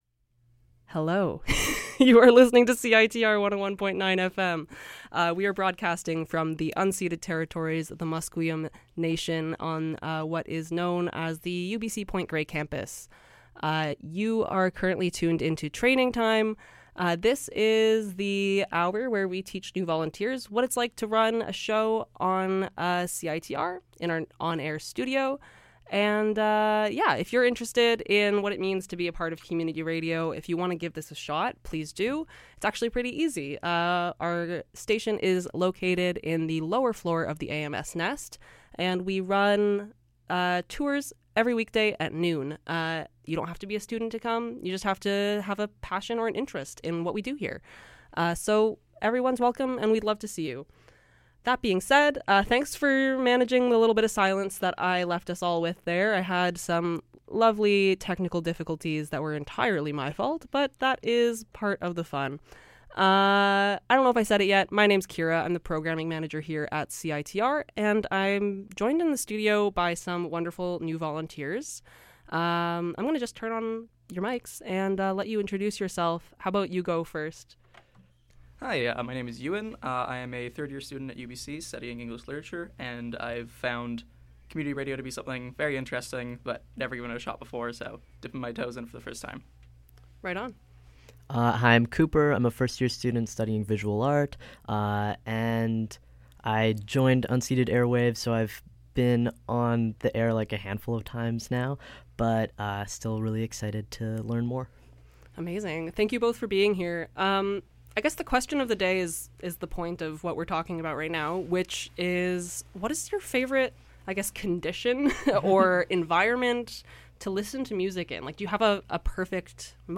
Join us to chat about music as new volunteers learn about the on-air studio at CiTR!